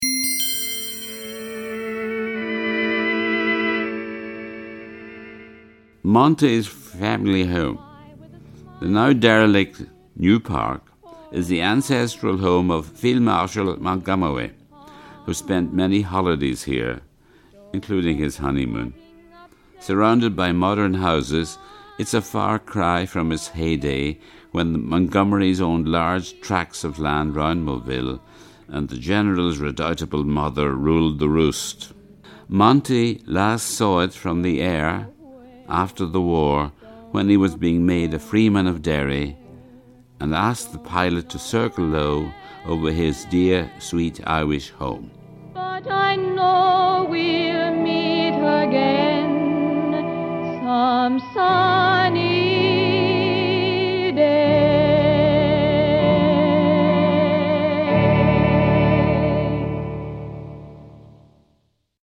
Here, he mildly takes the mick out of the notoriously pompous general with his great take on Monty's reminiscences!